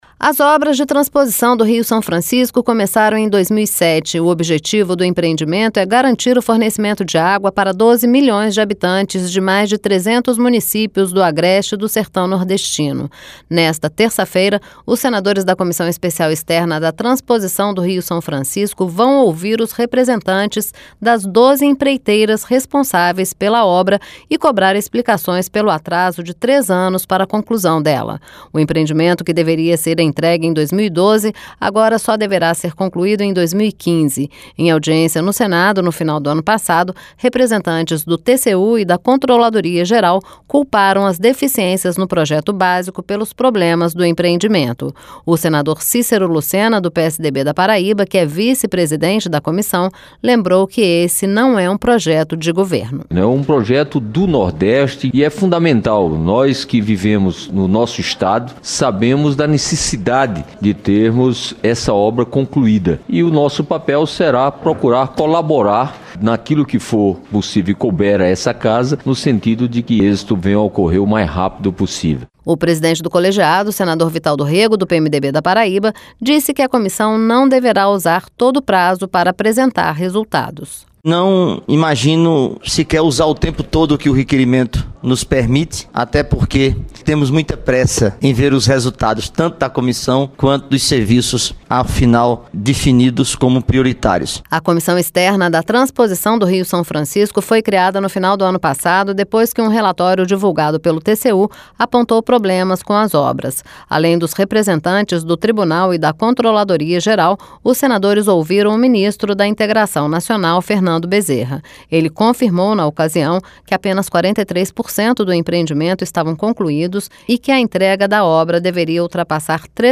LOC: OS SENADORES VÃO COBRAR EXPLICAÇÕES PELO ATRASO DE TRÊS ANOS PARA A CONCLUSÃO DO PROJETO. REPÓRTER